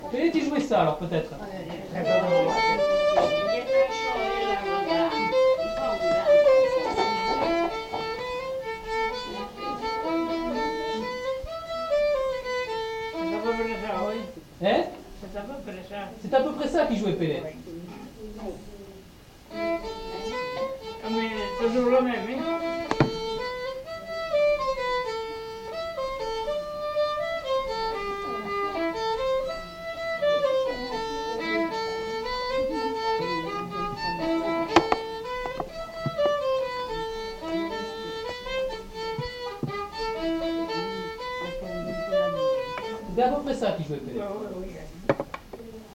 Lieu : Castillon-en-Couserans
Genre : morceau instrumental
Instrument de musique : violon
Danse : valse